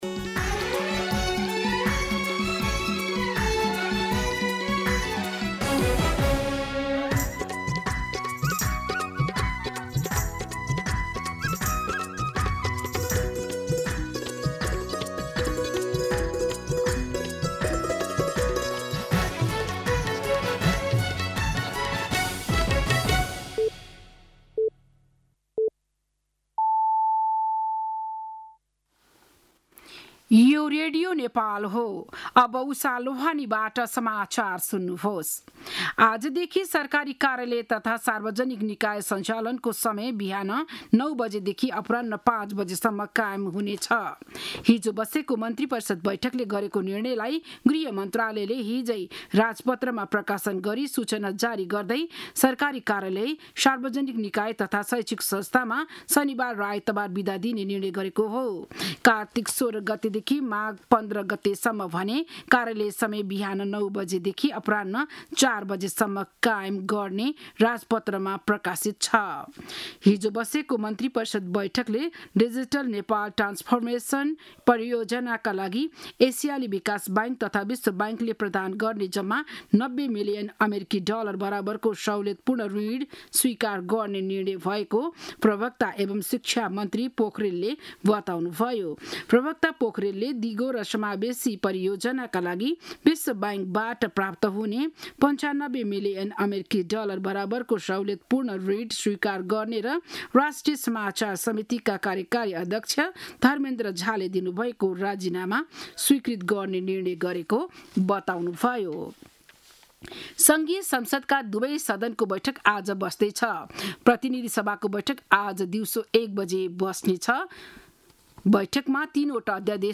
बिहान ११ बजेको नेपाली समाचार : २३ चैत , २०८२
11am-News-23.mp3